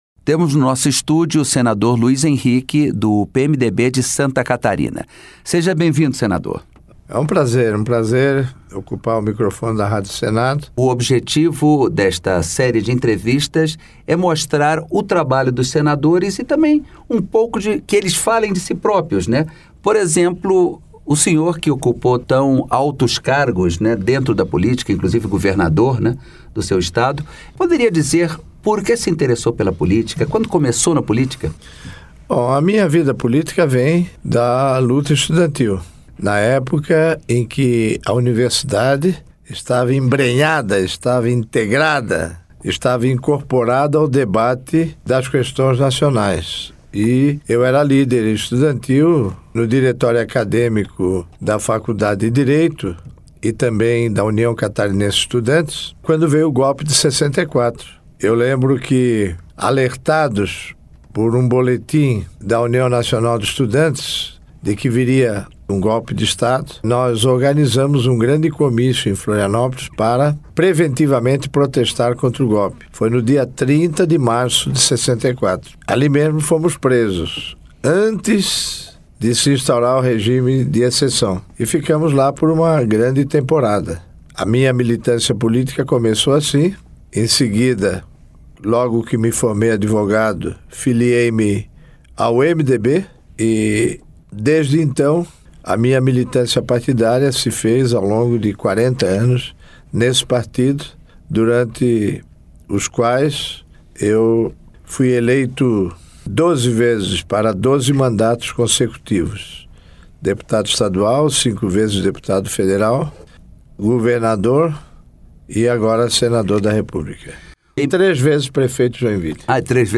Em entrevistas à Rádio Senado, senadores falam um pouco sobre sua história de vida
Entrevista com o senador Luiz Henrique